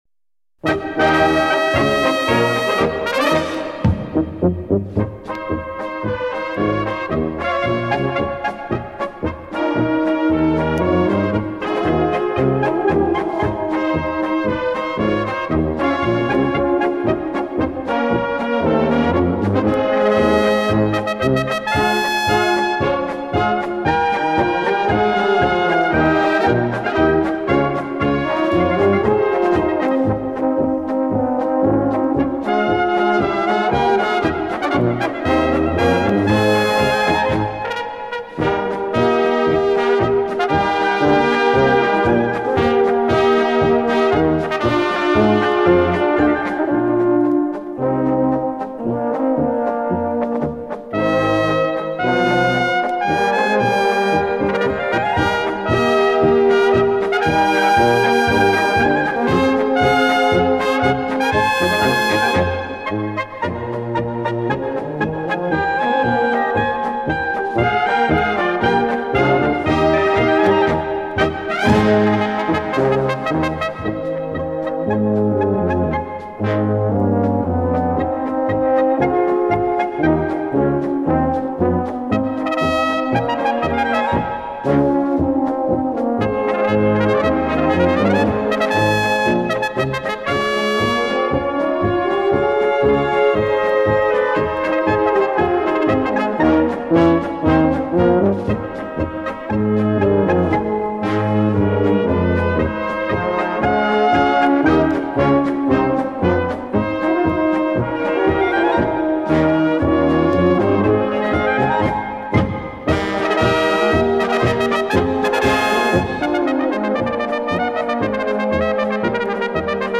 Gattung: Solo für Trompete und Blasorchester
Besetzung: Blasorchester